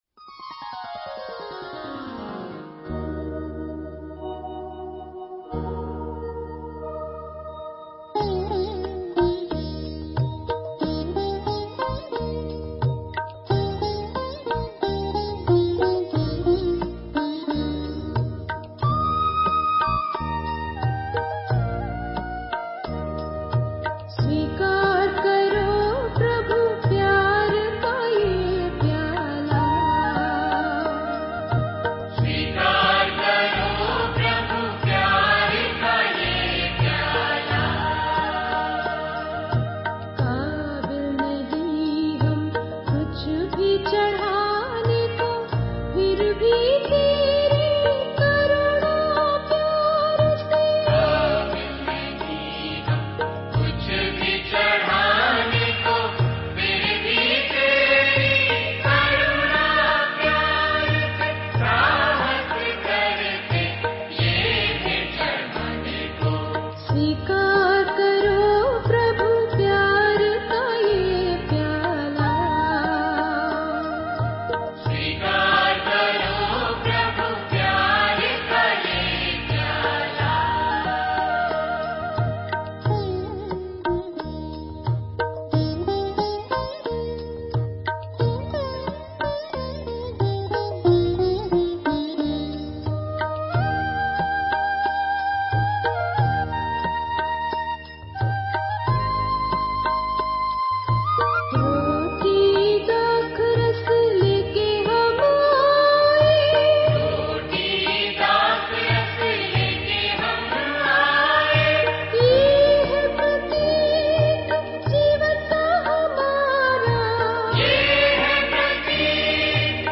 Directory Listing of mp3files/Hindi/Hymns/Bhakti Geet/ (Hindi Archive)
29 Swikar Karo Prabhu_Female Singer.mp3